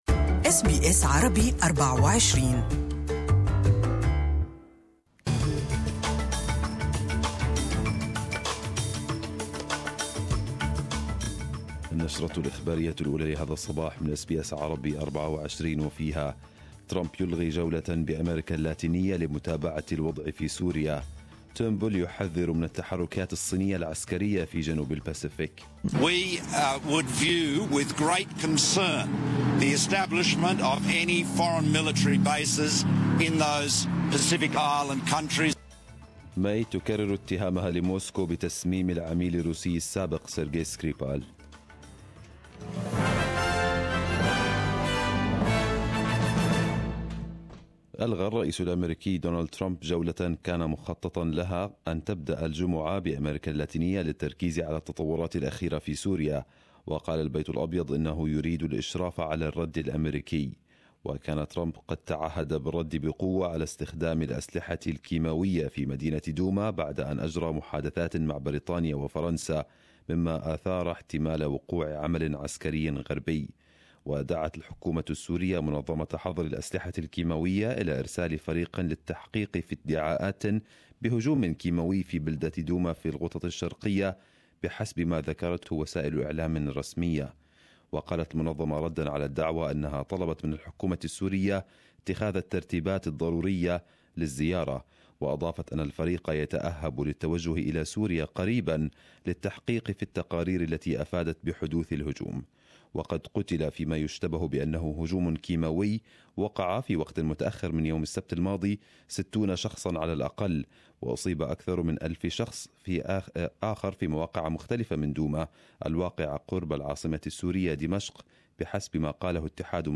Arabic News Bulletin 11/04/2018